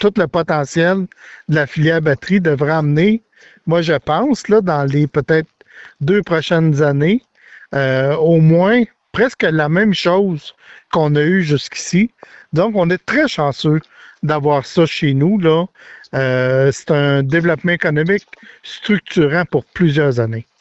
Le député de Nicolet-Bécancour, Donald Martel, en a glissé quelques mots lors d’une entrevue qu’il a accordée.